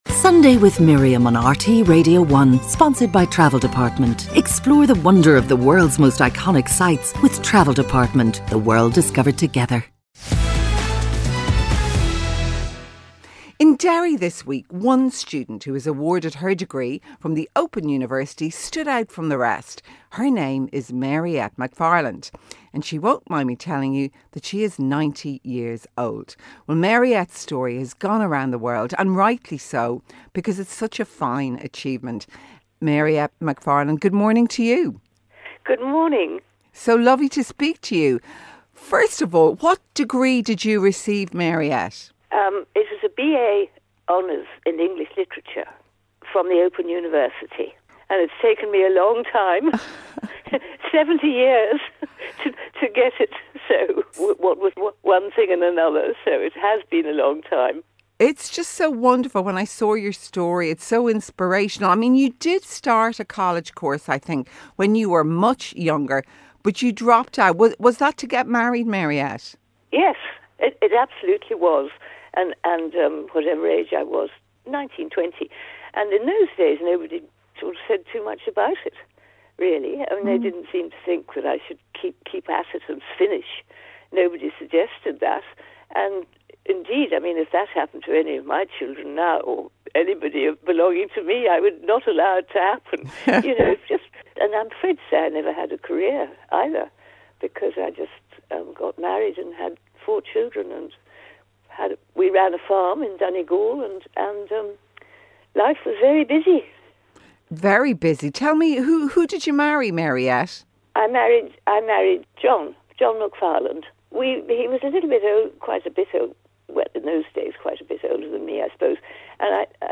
Miriam O'Callaghan presents an all-talking, all-singing, all-human-life-is-here show.